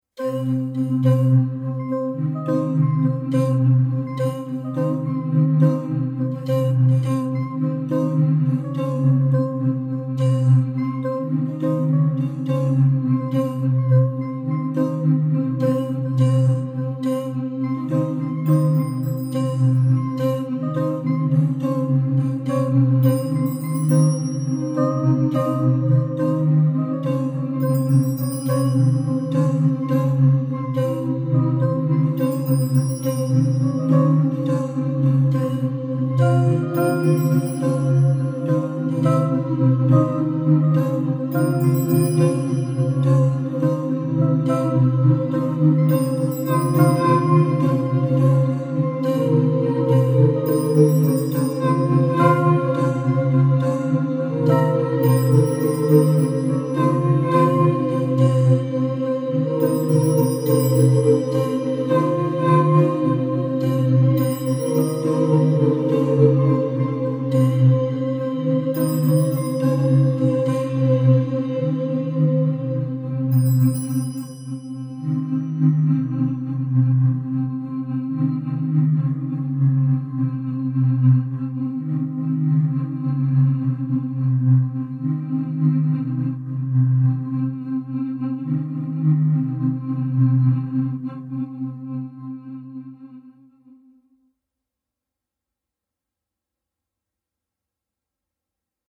reduced